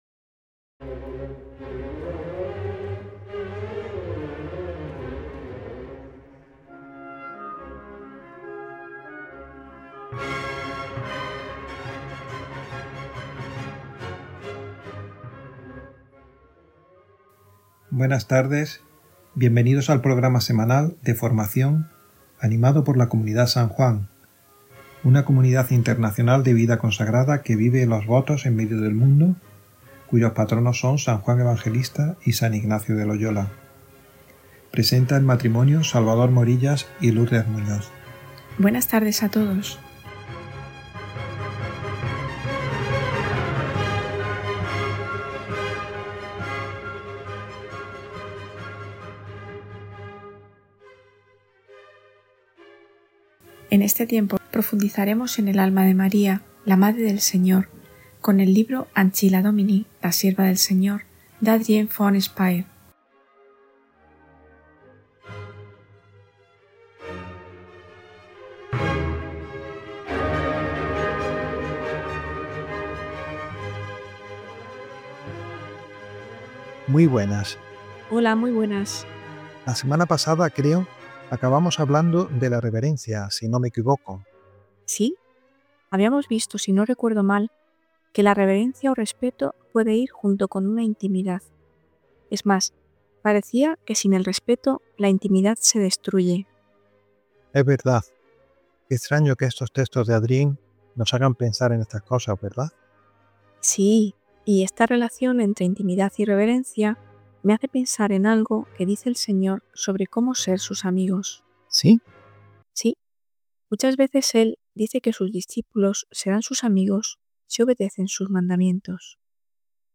El sí de la Virgen María y su entrega sin límites nos presenta el modelo de la actitud cristiana. Este podcast profundiza en esta realidad a través de la lectura y la meditación del libro Ancilla Domini, de la mística suiza Adrienne von Speyr.
Las Contemplaciones marianas de Adrienne von Speyr han sido transmitidas semanalmente en el marco del programa «Vida consagrada» de Radio María España entre noviembre 2022 y octubre 2024.